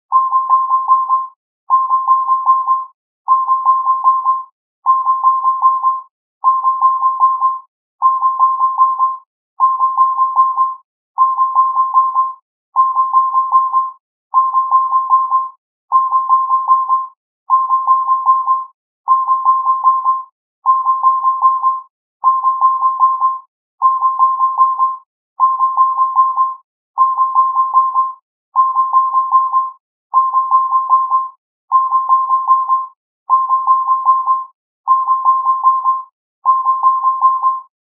連続した木琴の音の着信音。